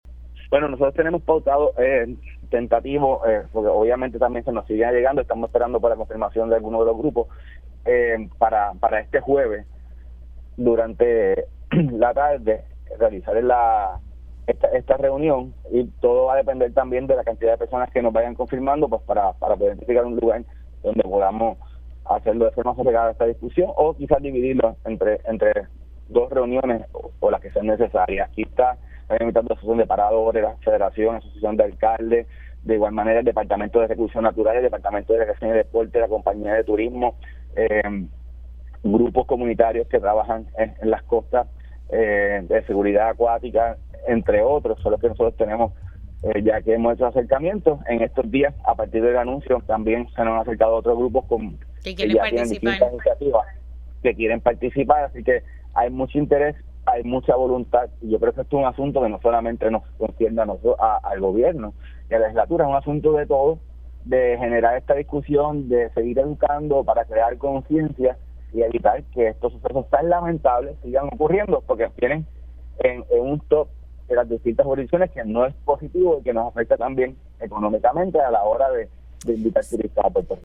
El representante argumenta que se debe trabajar no solamente a favor de la seguridad de los bañistas tanto locales como turistas, sino también argumenta que los incidentes de ahogamiento pudiesen impactar económicamente a la isla y que por ello se debe trabajar: